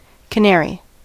Ääntäminen
Synonyymit informant Ääntäminen US : IPA : [kə.ˈnɛəɹ.i] Tuntematon aksentti: IPA : /kə.ˈnɛɹ.i/ Haettu sana löytyi näillä lähdekielillä: englanti Käännös Substantiivit 1.